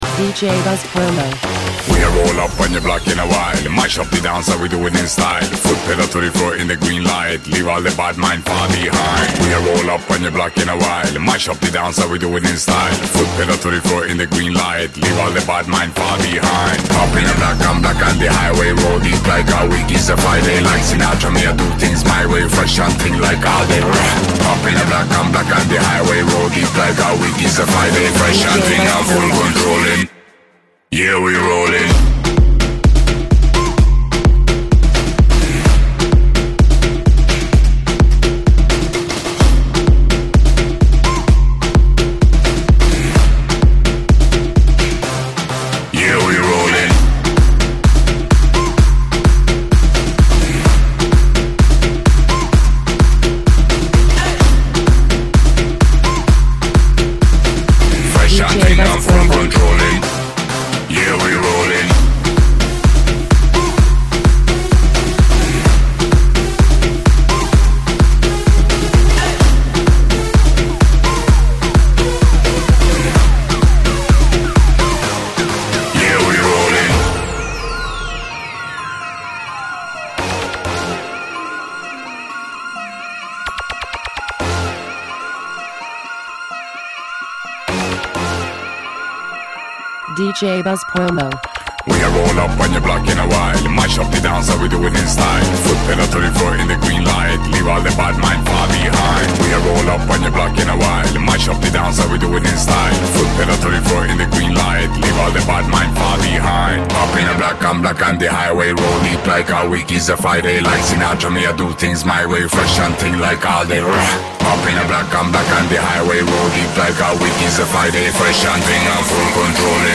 electronica
Original Mix